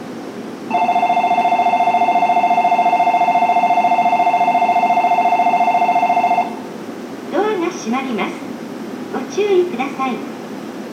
倉賀野駅　Kuragano Station ◆スピーカー：ユニペックス小丸型
乗降が少ないため上下線ともベルは長く鳴らしません。
1番線発車ベル